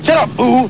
The A-Team TV Show Sound Bites